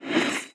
sword.wav